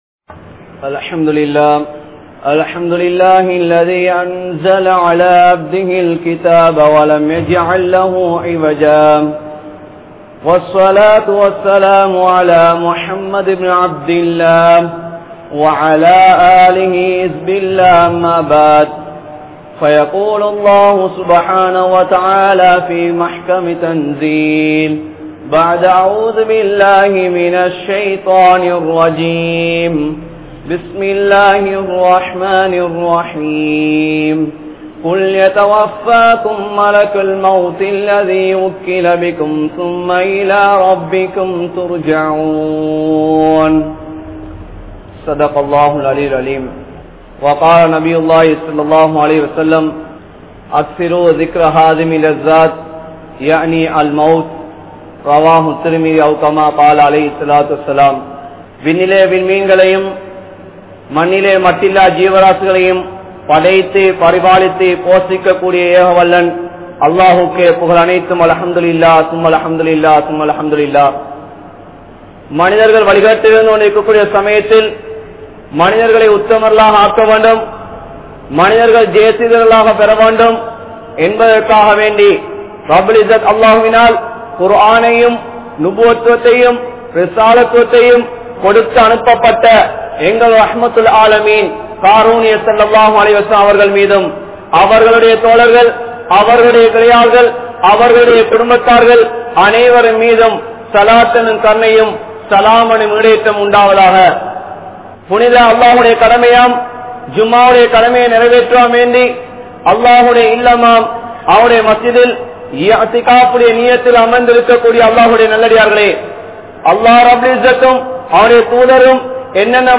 Remembering the Death | Audio Bayans | All Ceylon Muslim Youth Community | Addalaichenai
Thaqwa Jumua Masjith